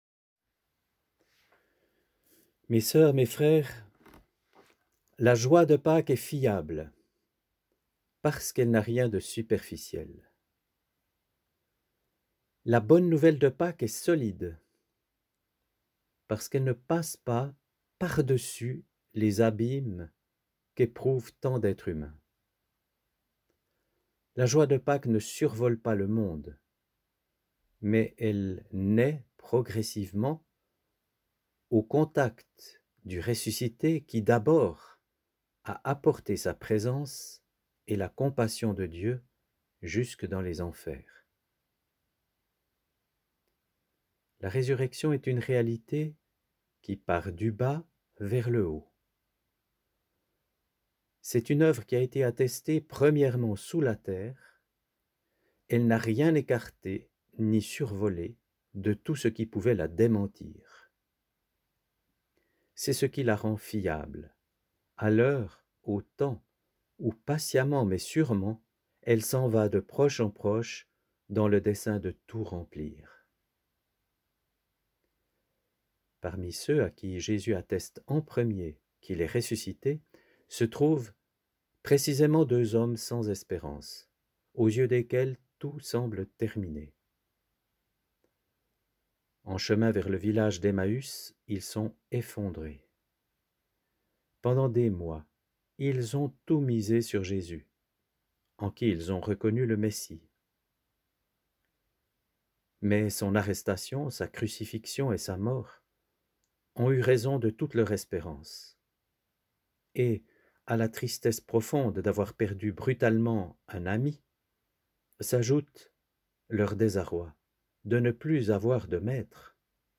G-Méditation-Jour-de-Pâques.mp3